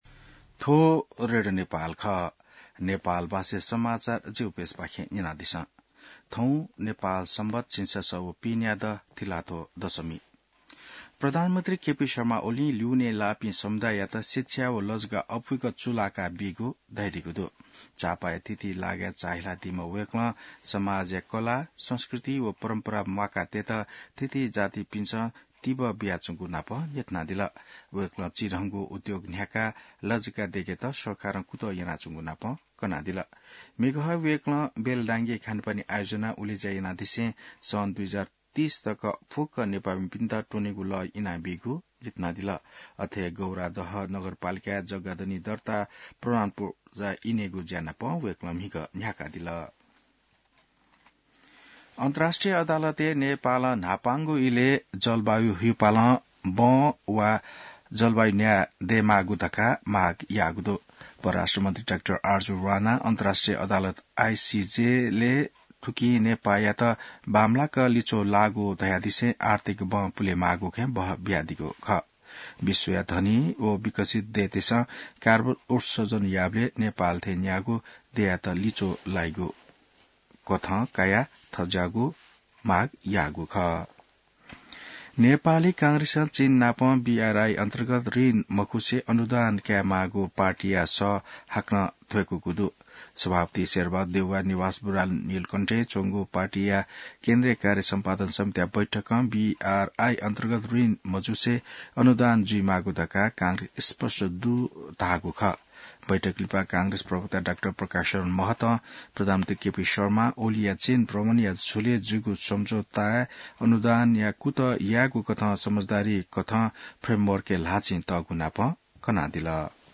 An online outlet of Nepal's national radio broadcaster
नेपाल भाषामा समाचार : २६ मंसिर , २०८१